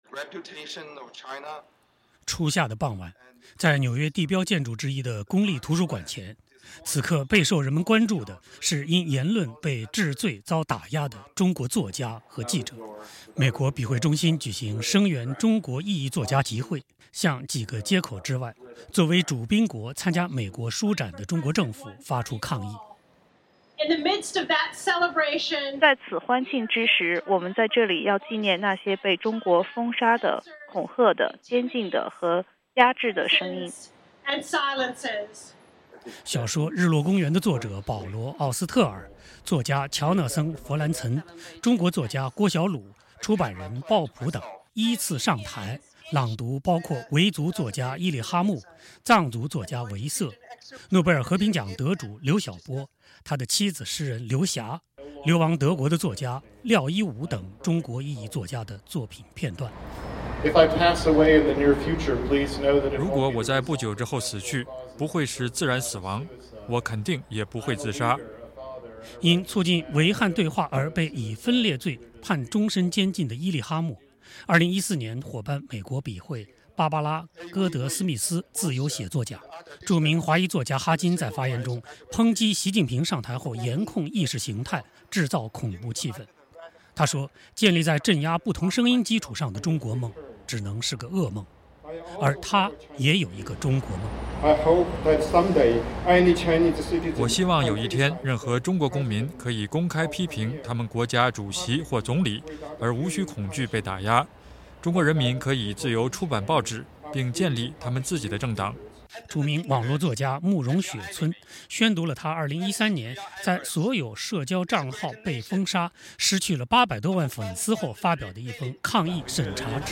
纽约 —
星期三，中国作为主宾国参加纽约美国书展开幕当天，美国笔会中心举行抗议中国审查制度、声援被监禁中国异议作家的集会。著名华裔作家哈金说，他也有一个中国梦，那就是中国公民可以自由出版报纸、建立政党。